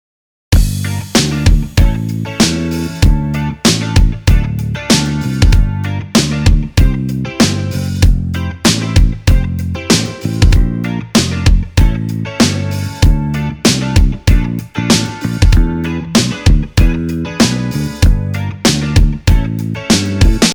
Помогите со сведением на примере куплета
Пытался свести бочку с басом именно так как в минусе, но не могу понять что и как крутить.